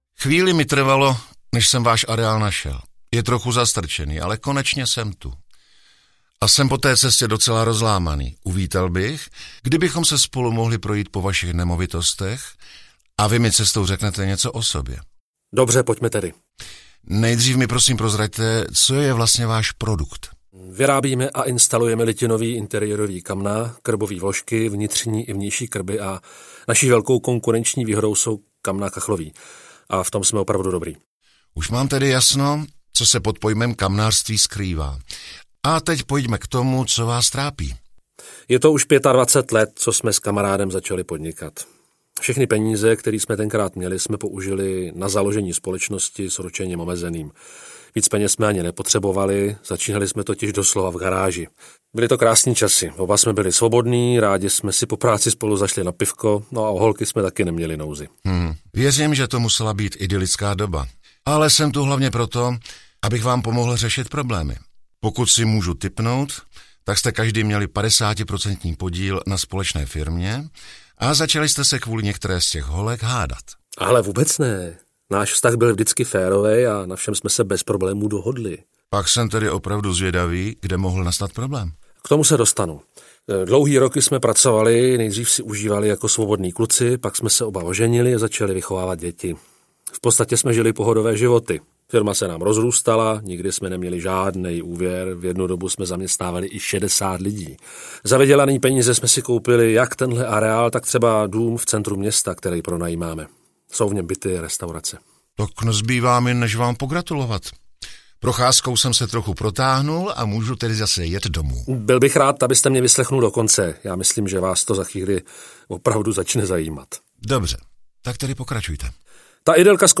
Krotitel rizik podnikání zasahuje: Kamnářství audiokniha
Ukázka z knihy
Spojení zajímavých příběhů s hlasy známých českých herců podtrhuje atraktivitu celého projektu. Třetí díl je hodně dramatický. Vdova po padesátiprocentním společníkovi se nechce o nic dělit a na svého obchodního partnera dost nevybíravě útočí.